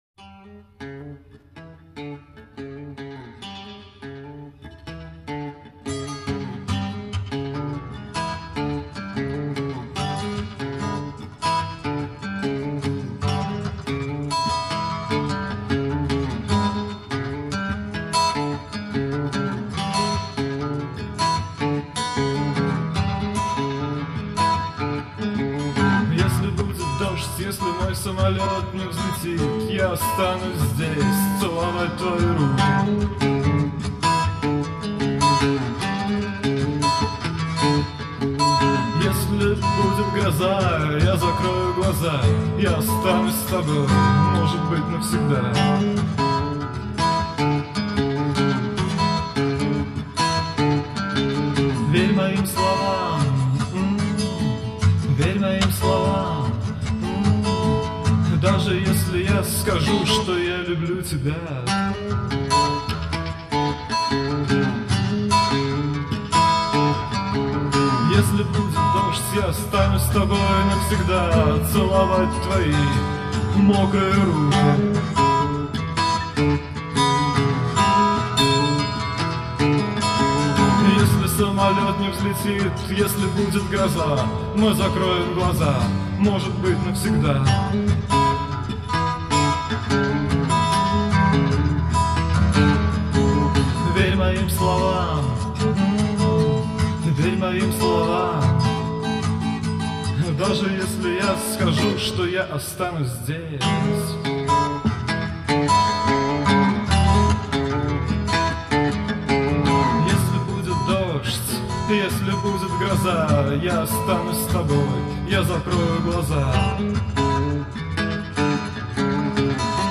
губная гармошка, голос.